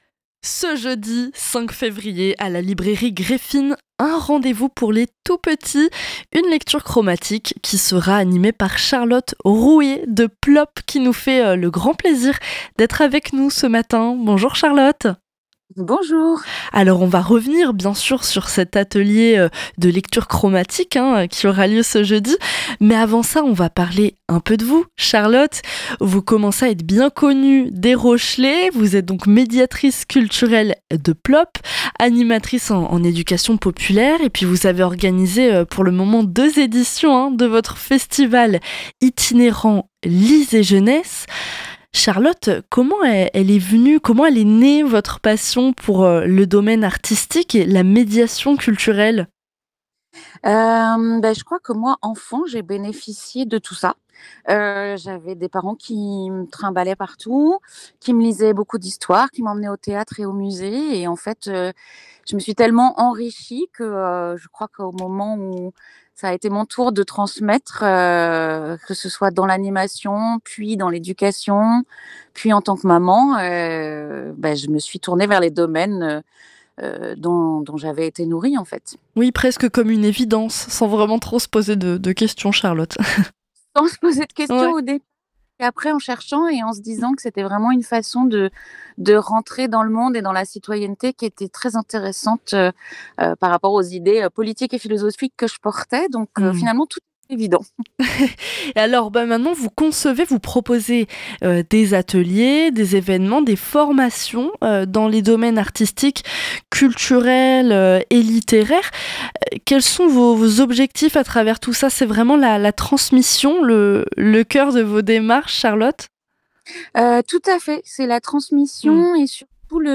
Blues-rock électrique américain sans concession